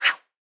swordhit2.wav